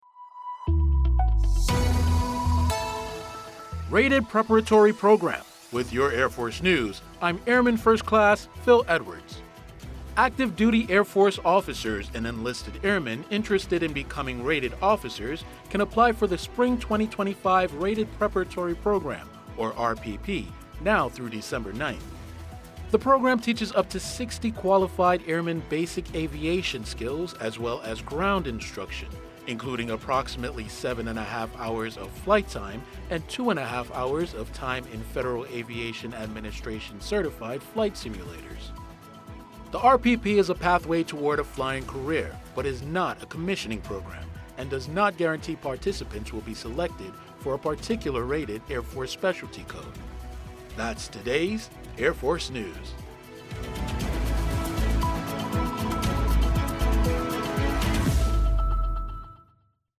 Features audio news stories involving U.S. Air Force technology, personnel, and operations around the globe.